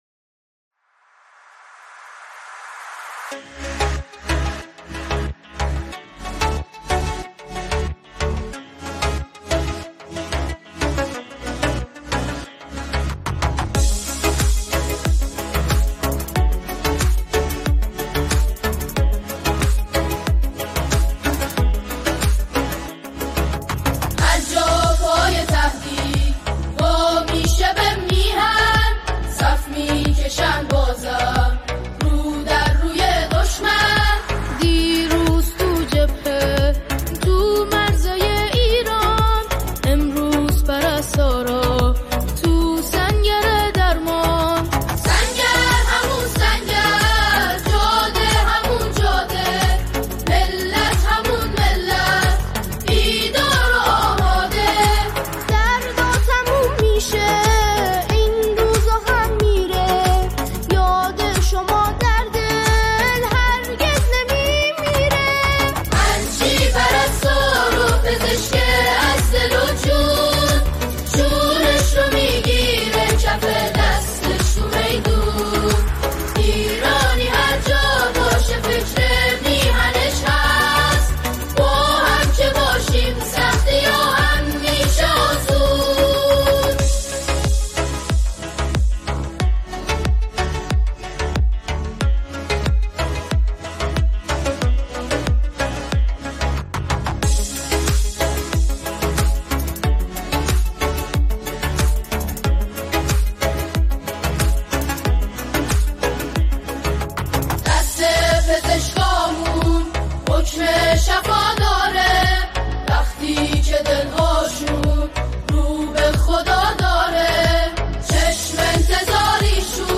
بی کلام
اثری است عمیق، احساسی و انگیزشی